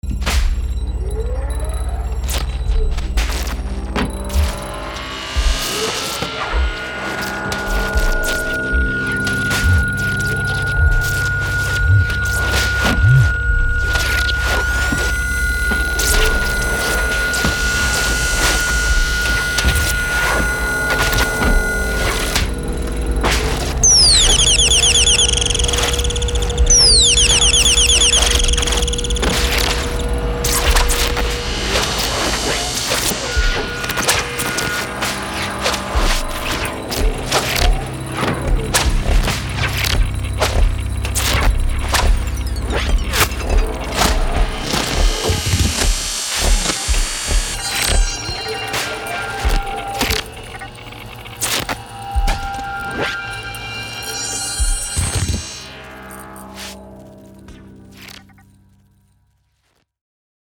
Zaps Hums Tones
Cinematic